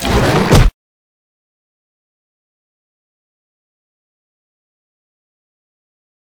vpunch2.ogg